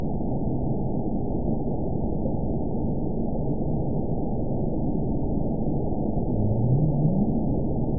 event 914248 date 05/02/22 time 00:52:00 GMT (3 years ago) score 9.26 location TSS-AB01 detected by nrw target species NRW annotations +NRW Spectrogram: Frequency (kHz) vs. Time (s) audio not available .wav